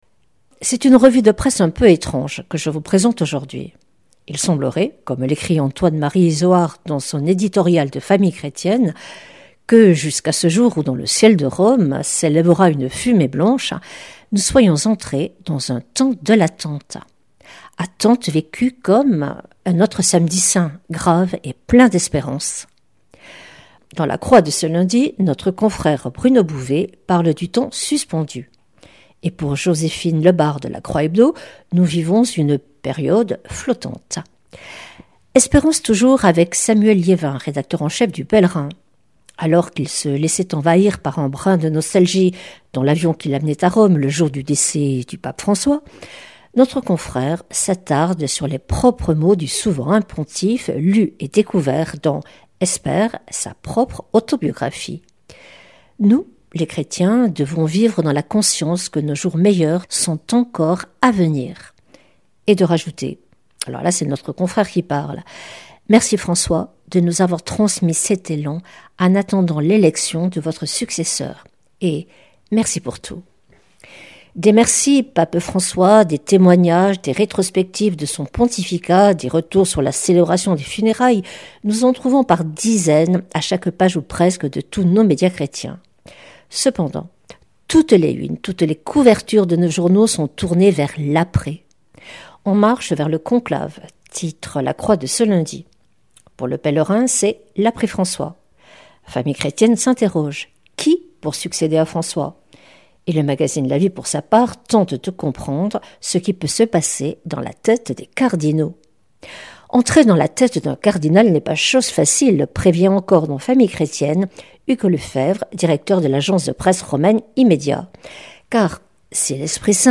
Revue de presse
Une émission présentée par